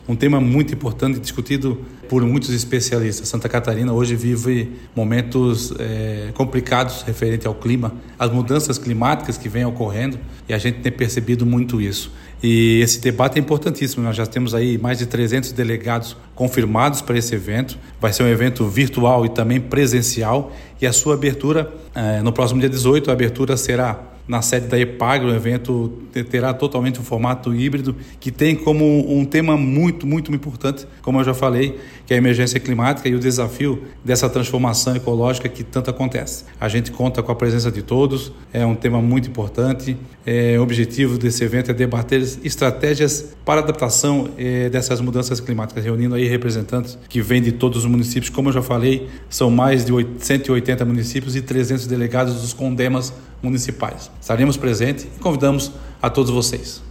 O secretário do Meio Ambiente e da Economia Verde, Emerson Stein, fala da importância do evento: